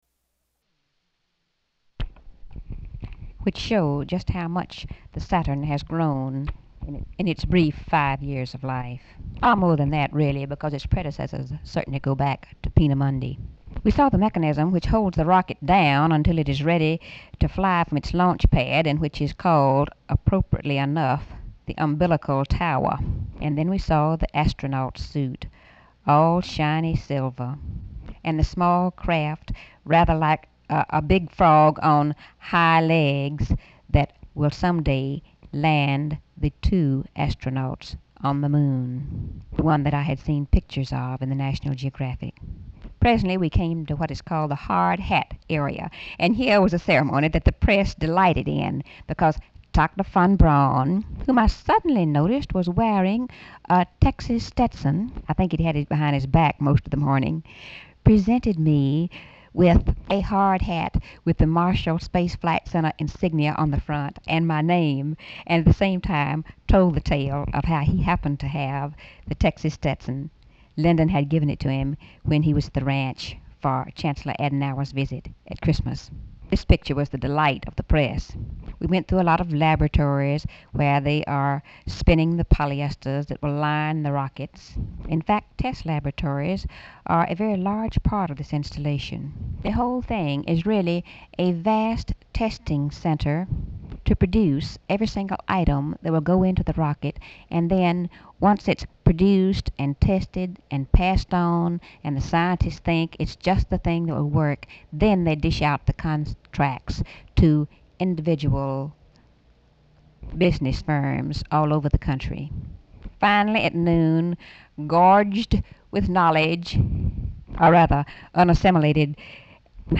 Audio tape
White House, Washington, DC